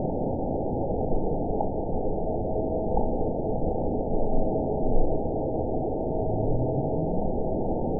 event 912048 date 03/16/22 time 21:31:18 GMT (3 years, 2 months ago) score 9.62 location TSS-AB03 detected by nrw target species NRW annotations +NRW Spectrogram: Frequency (kHz) vs. Time (s) audio not available .wav